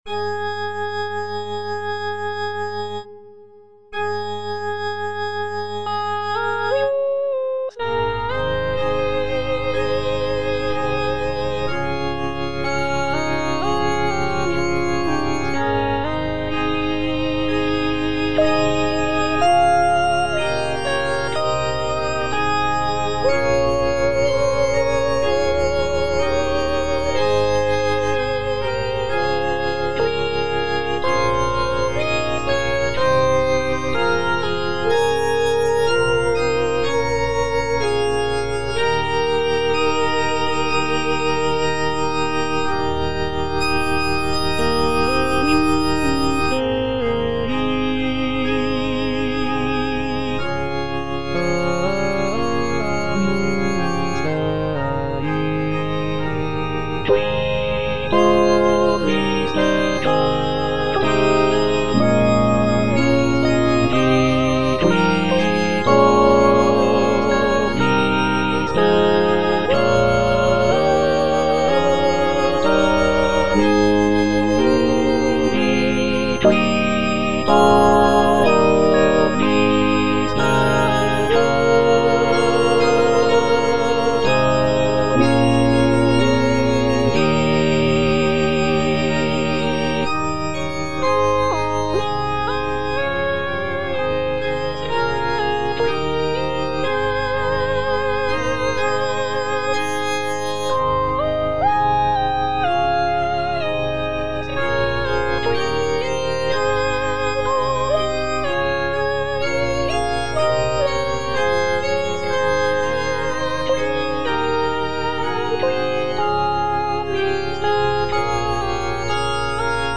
Tenor (Emphasised voice and other voices) Ads stop
is a sacred choral work rooted in his Christian faith.